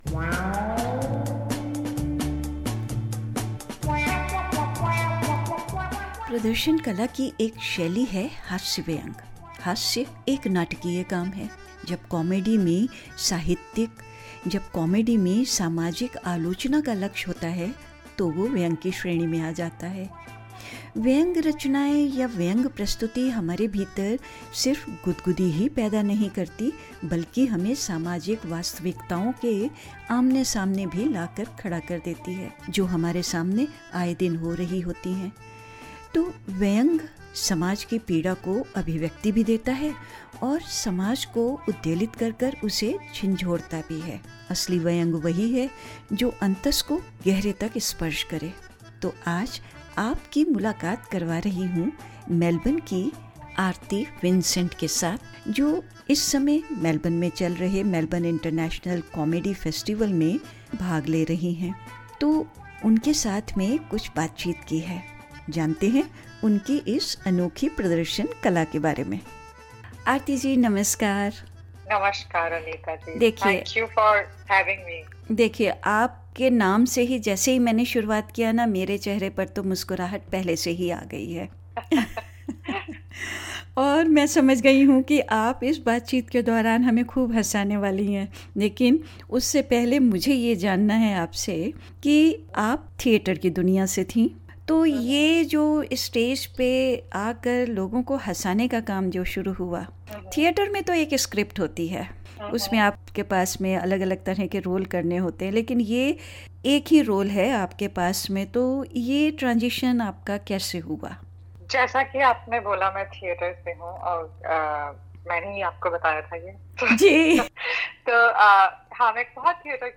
Speaking with SBS Hindi, she shares her journey into the world of comedy and talks about the person in her life who takes all her jokes with pride.